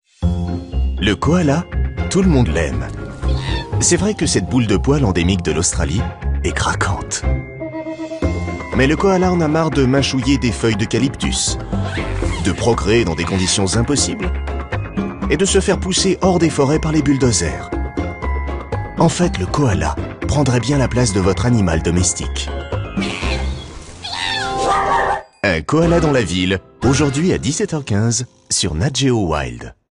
Prestation Voix-off douce et humoristique pour "Un Koala dans la ville"
Doux, conteur et humour.
Ce projet a été pour moi l’occasion de manier avec finesse et dextérité ma voix médium grave, pour offrir à ce documentaire une ambiance unique, mélange de douceur et d’humour.
Le ton naturel et calme de ma voix a permis de créer un contraste avec l’agitation de la ville, accentuant ainsi l’aspect comique de la situation.